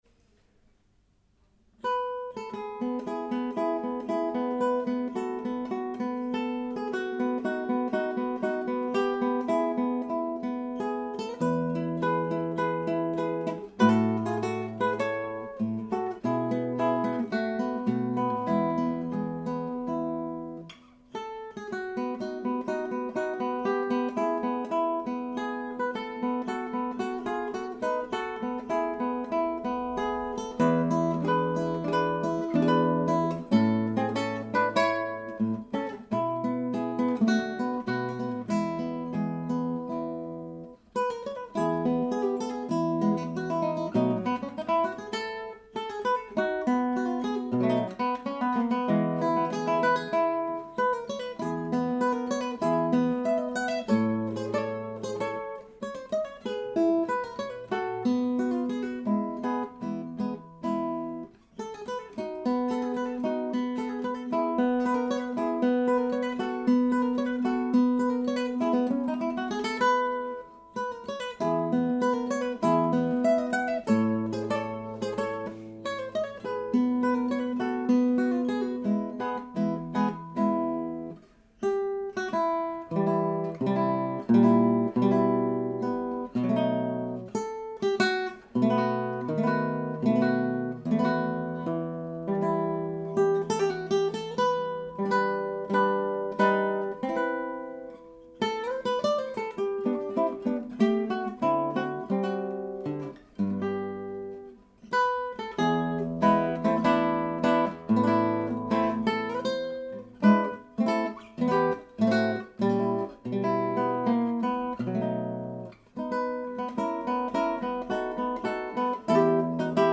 That is the Citronics, I would expect the T669 to be AS good, maybe a lower noise floor being a 24 bit device?